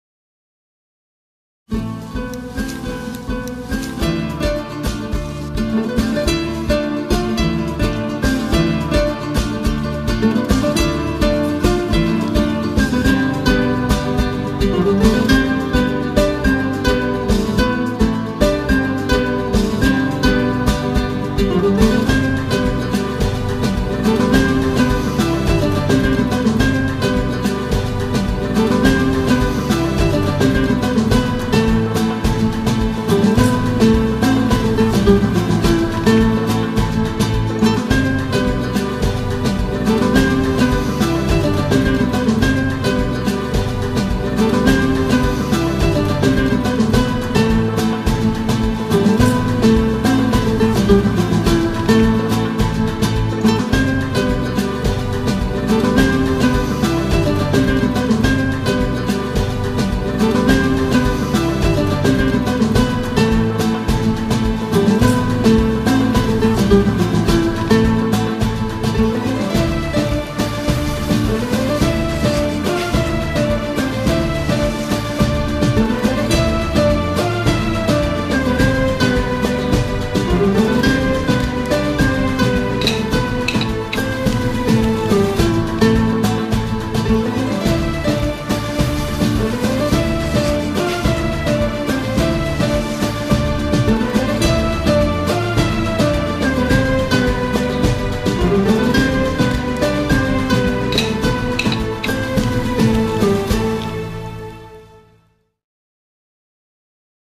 tema dizi müziği, mutlu huzurlu rahatlatıcı fon müzik.